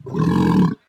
sounds_sea_lion_01.ogg